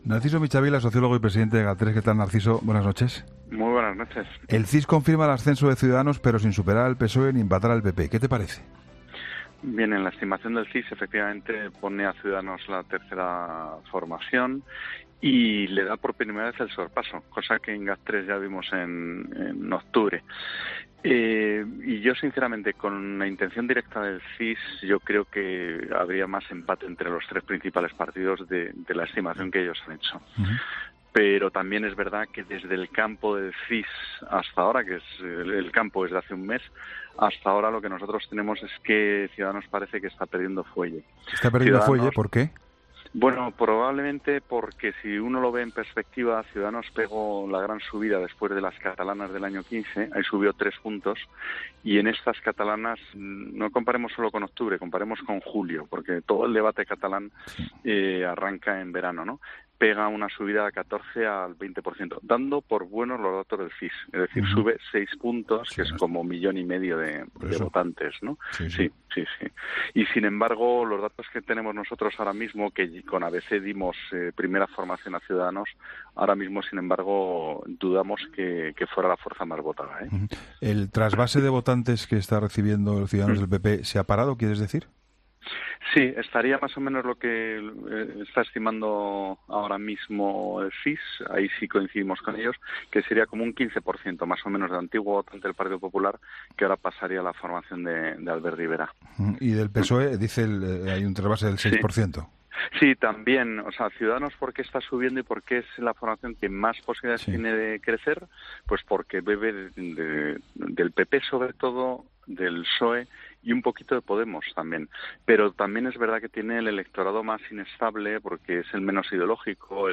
Entrevistas en La Linterna